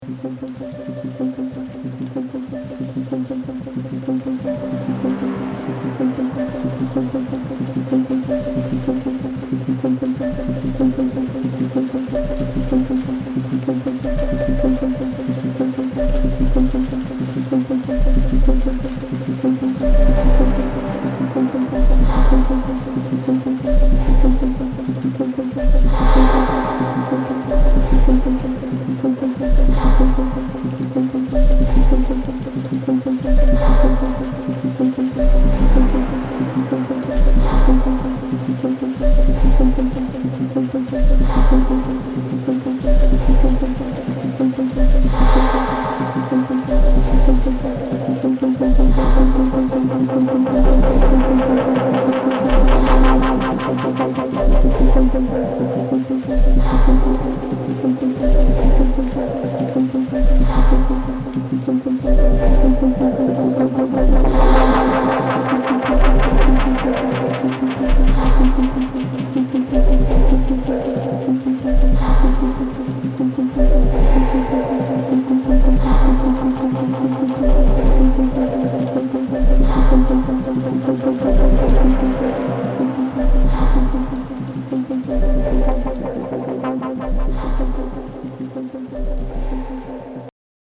105BPMヴォーカルチューン